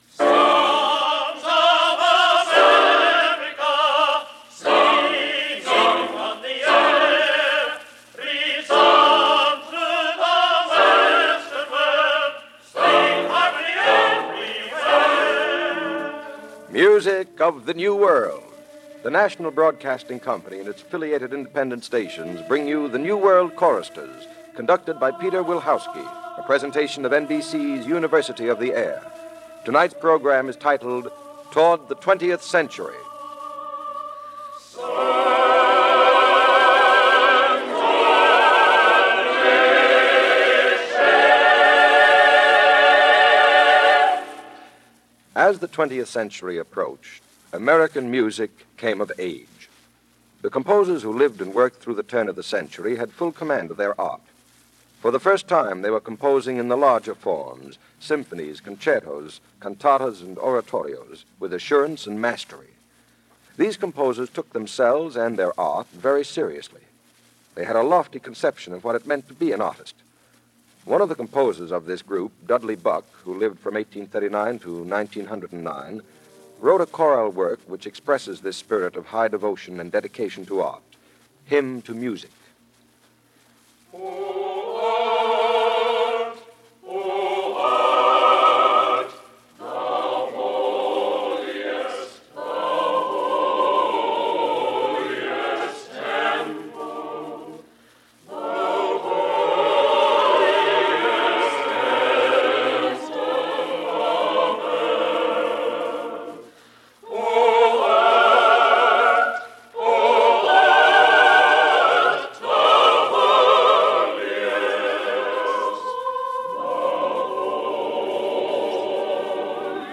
Turn-Of-The-Century American Music For Choir - 1944 - Past Daily Weekend Gramophone
Turn-of-the-century American Music For Choir - 1944 - American composers from 1860-1903 - music for Choir - August 3, 1944 - Past Daily Weekend Gramophone.